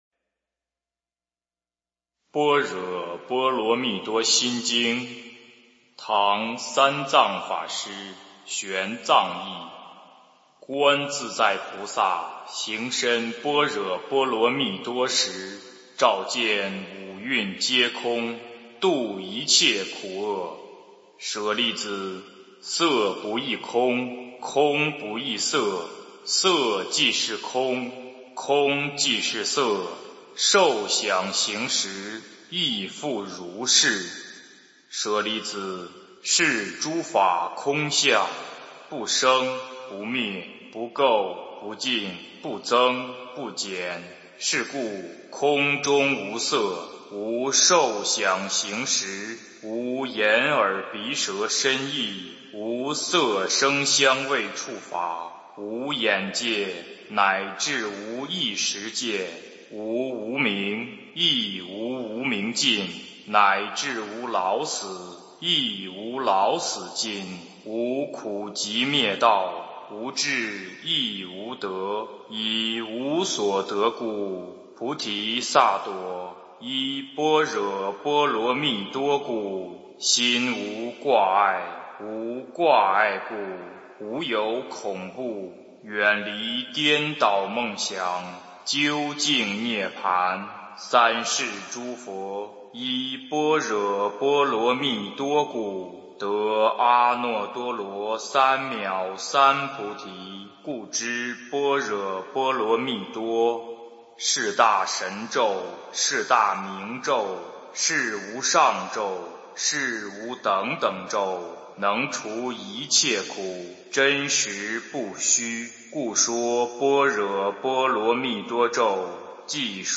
心经 (念诵)
诵经 心经